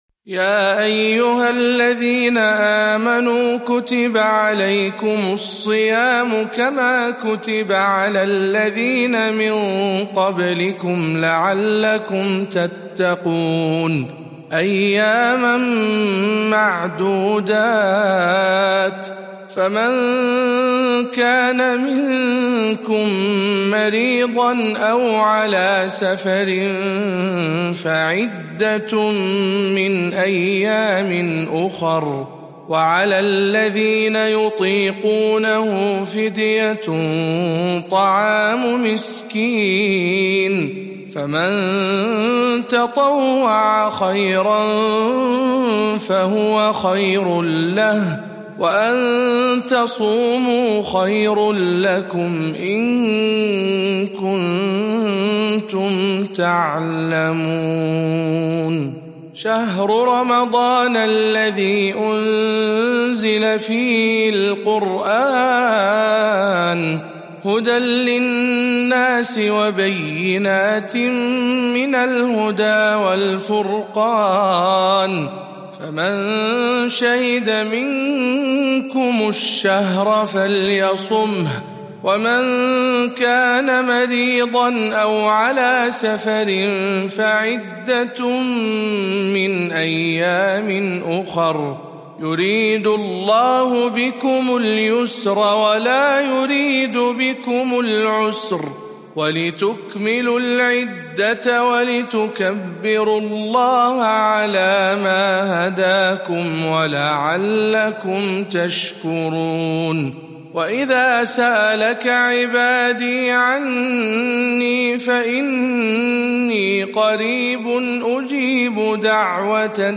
آيات الصيام - برواية حفص عن عاصم